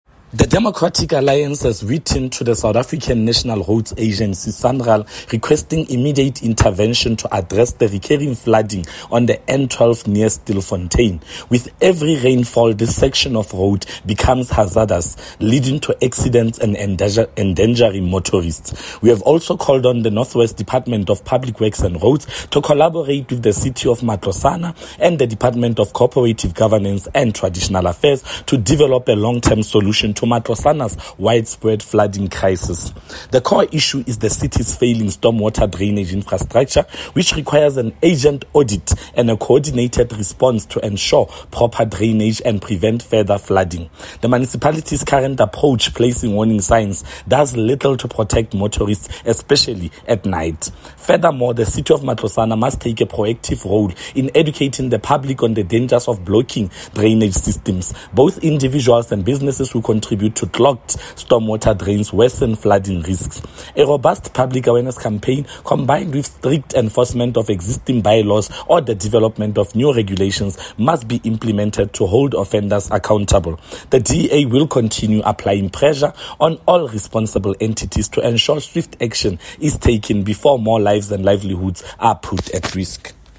Note to Broadcasters: Please find attached soundbite in